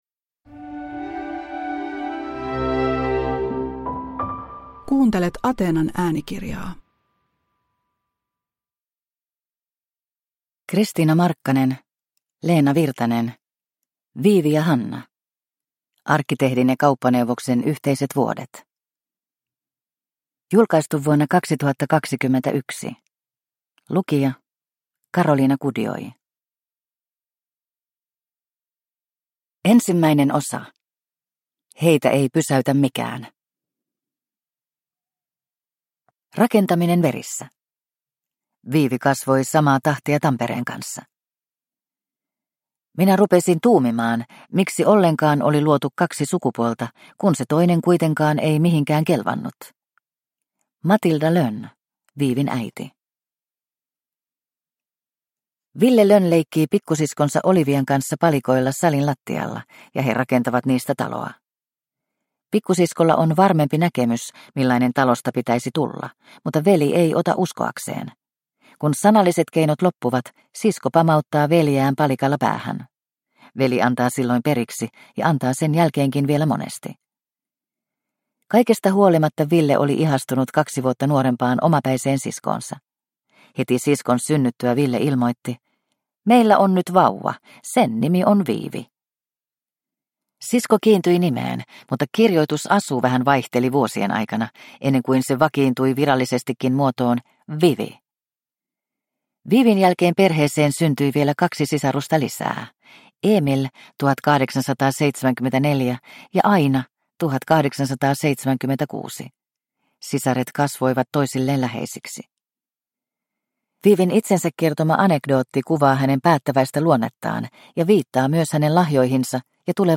Monipuolinen ja lämmöllä tulkittu elämäkerta lasikattoja pirstoneesta naiskaksikosta.